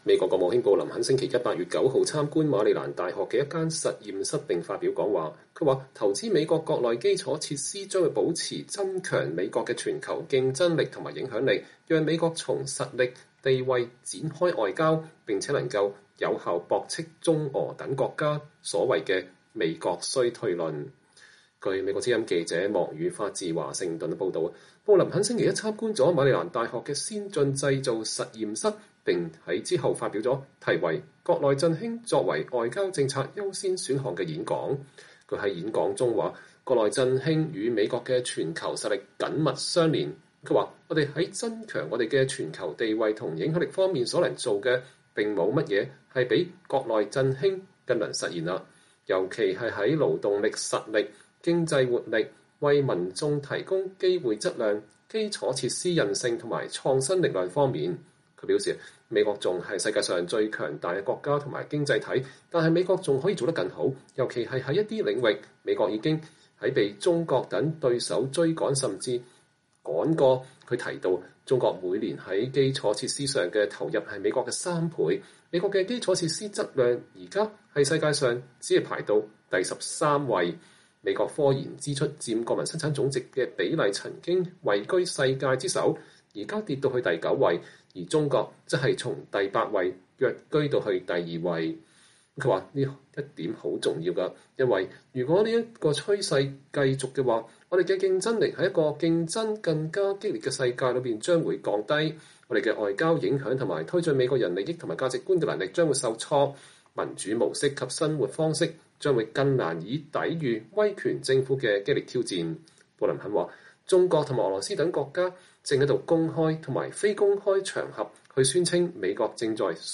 美國國務卿布林肯8月9日參觀馬里蘭大學工程學院並發表有關基礎設施投資的演講。